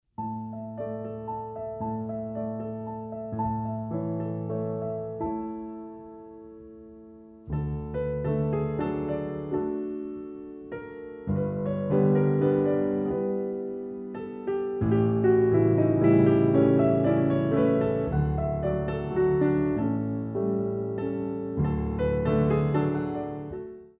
Young dancers Ballet Class
The CD is beautifully recorded on a Steinway piano.
Pliés